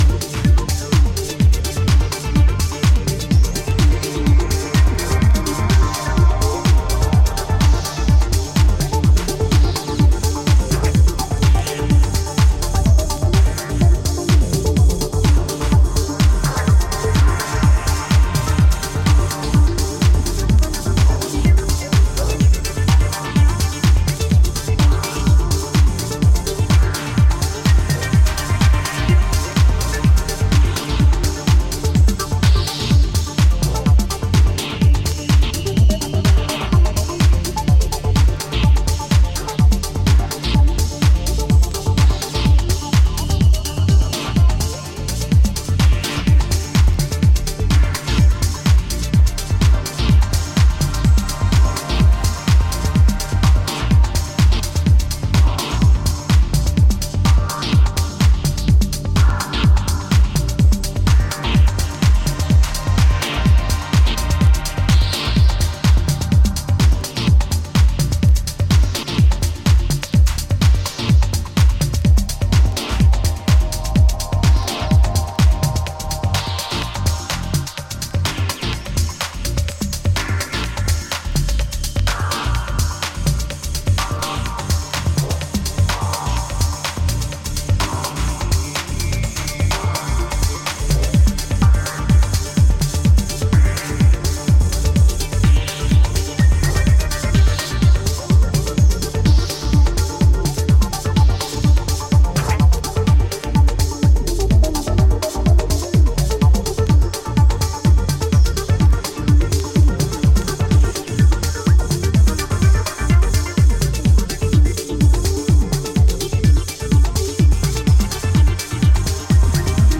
ここでは、抑制の効いたメロディアスでサイケデリックなレイヤーを配し、秀逸なミニマル・テック・ハウスを展開。